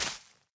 assets / minecraft / sounds / block / moss / break3.ogg
break3.ogg